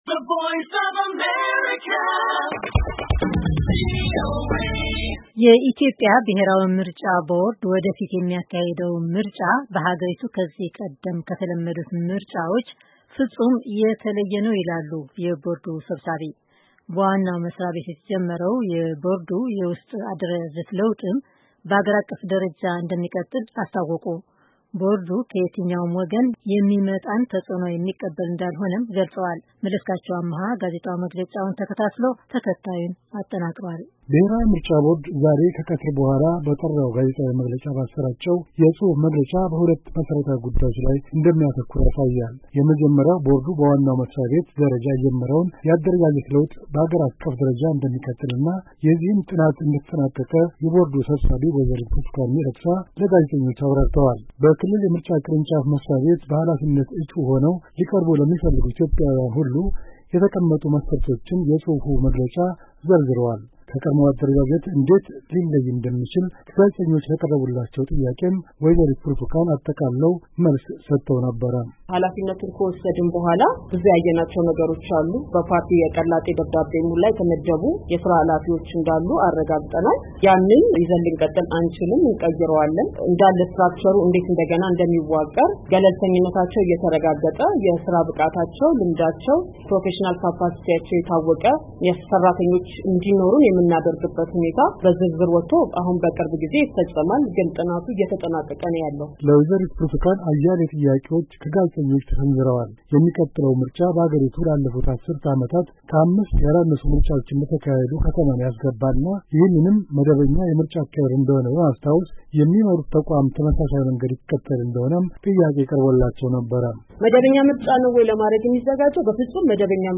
የኢትዮጵያ ብሔራዊ ምርጫ ቦርድ ወደፊት የሚያካሂደው ምርጫ በሃገሪቱ ከዚህ ቀደም ከተለመዱት ምርጫዎች ፍፁም የተለየ ይሆናል” ሲሉ የቦርዱ ሰብሳቢ ወ/ት ብርትኳን ሚደቅሳ ዛሬ በሰጡት መግለጫ ተናግረዋል።